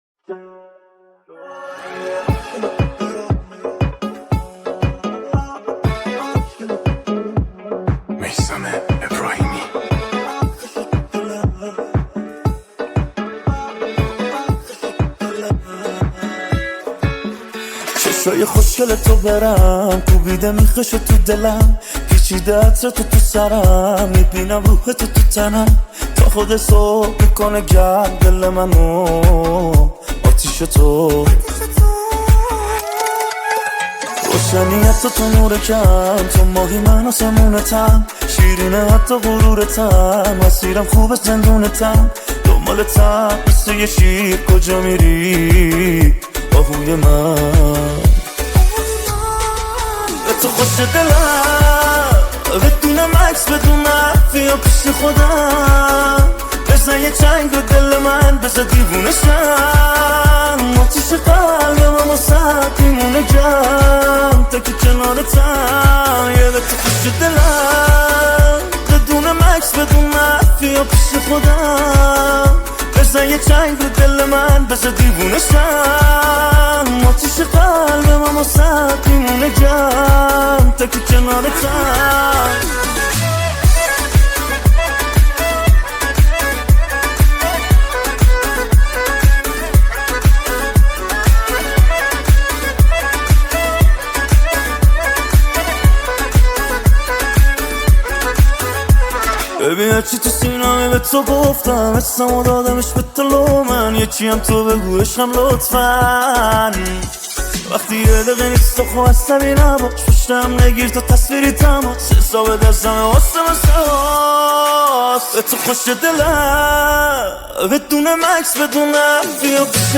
آهنگ شاد
ریمیکس شاد ، ریمیکس عاشقانه